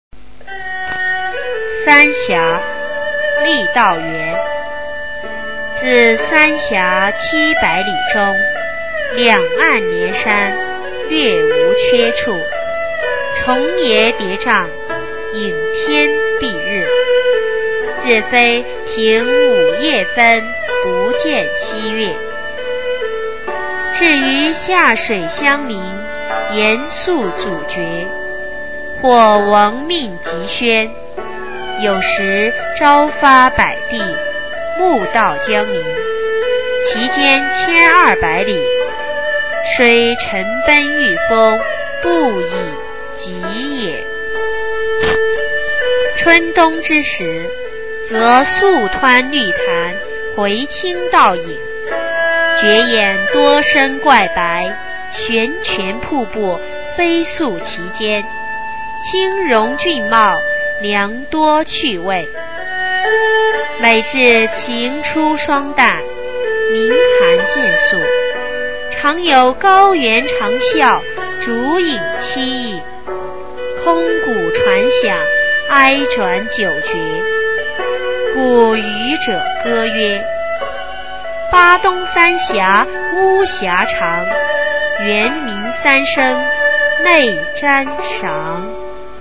郦道元《三峡》原文和译文（含在线朗读）　/ 郦道元